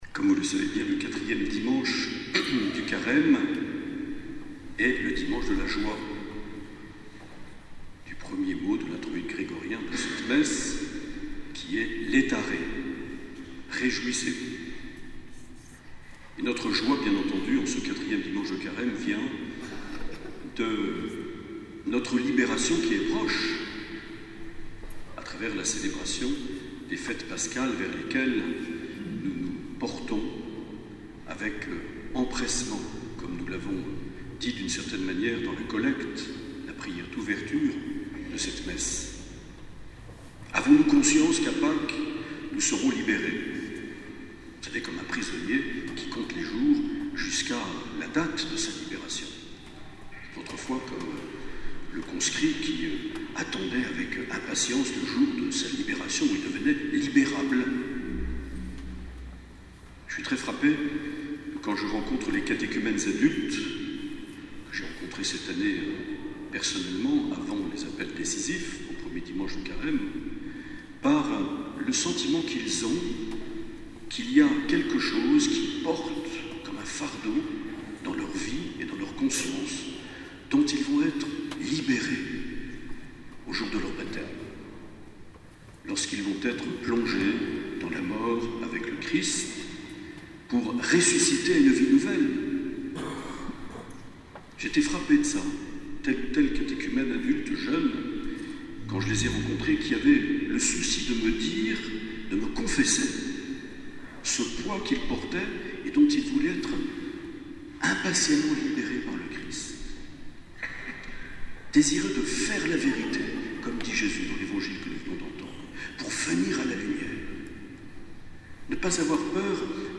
Les Homélies
Une émission présentée par Monseigneur Marc Aillet